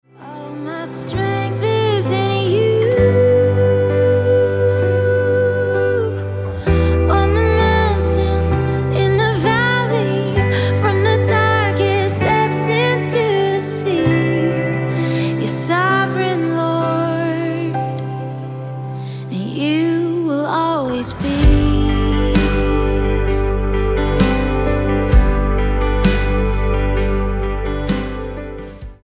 Style: Pop Approach: Praise & Worship